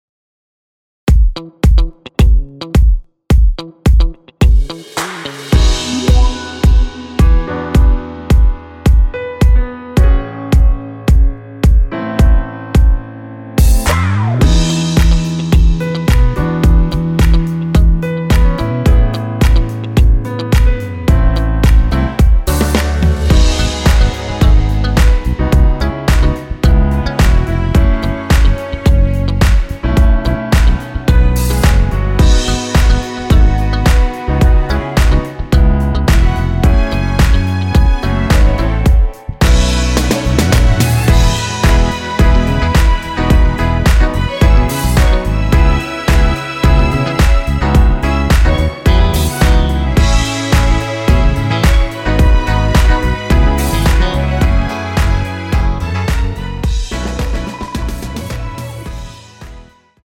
MR 입니다.